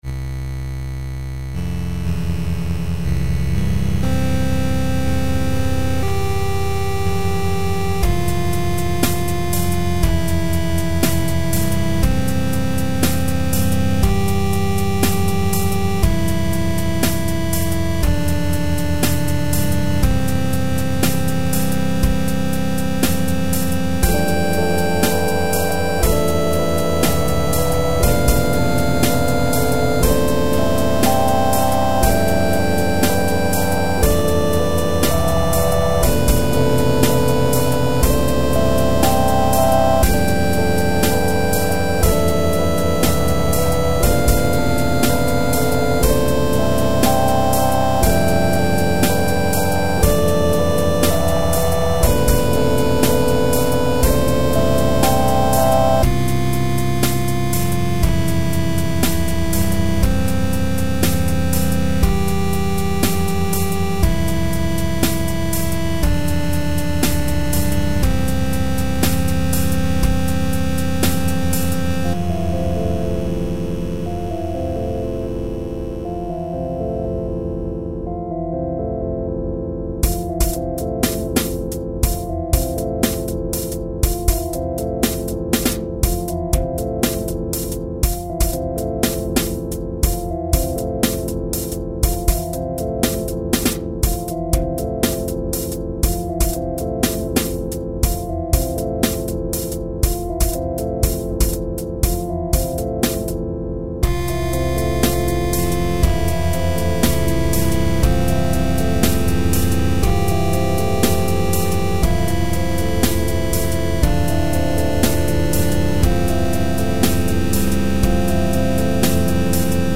Filed under: Instrumental | Comments (1)